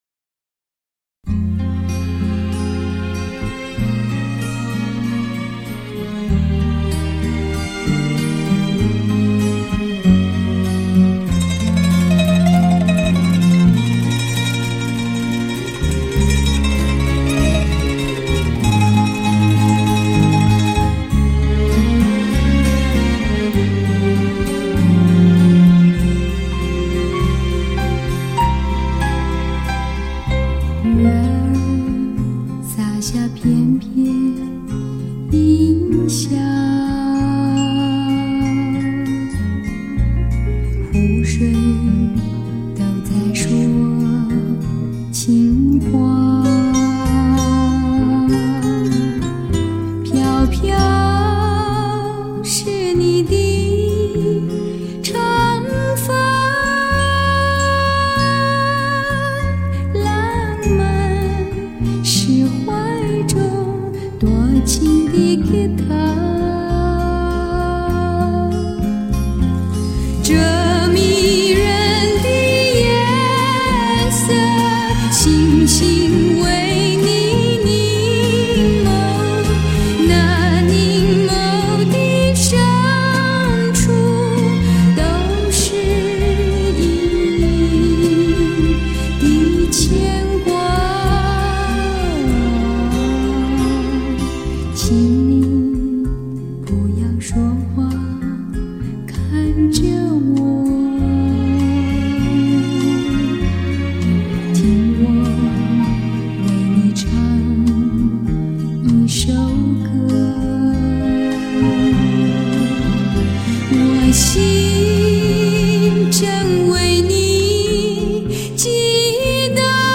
原始母带 24Bit 音质处理 发烧典藏